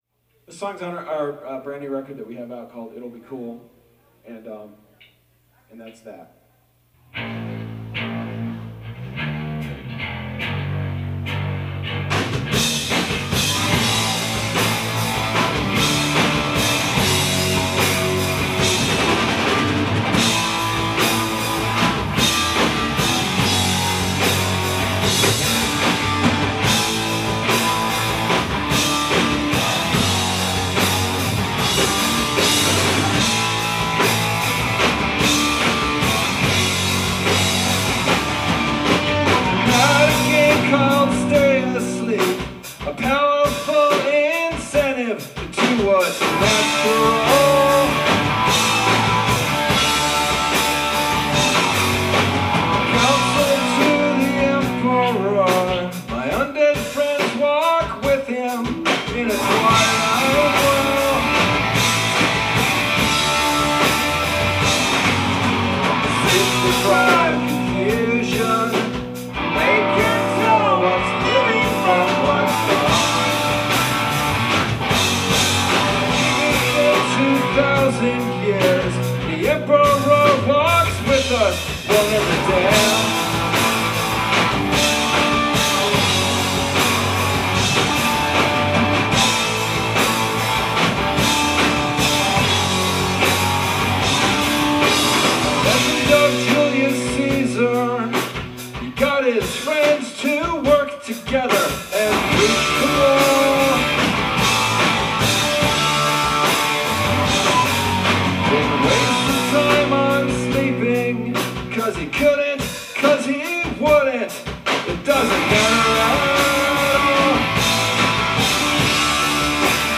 Allston, MA, Nov. 11th, 2004